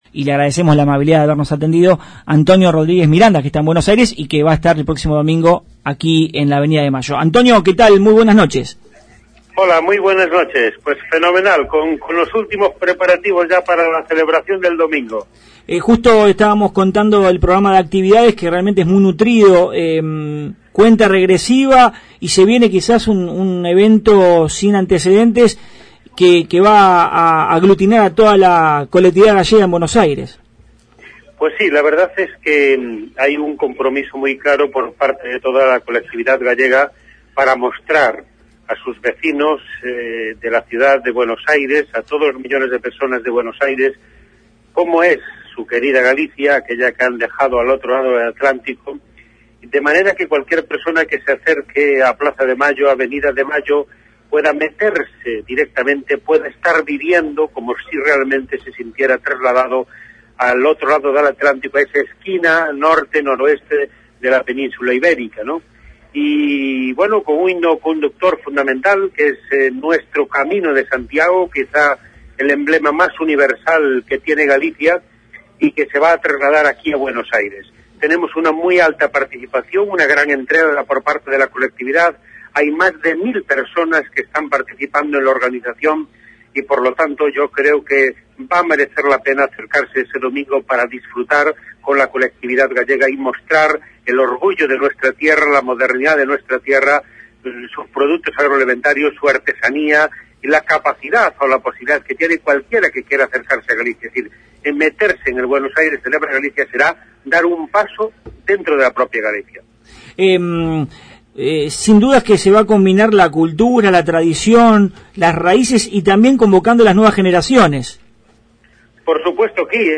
Trátase dun espazo “que pon en valor os tesouros culturais, turísticos, gastronómicos, e artesanais de Galicia, en definitiva a cultura e calidade galegas”, segundo expresión do propio secretario xeral da Emigración, Antonio Rodríguez Miranda.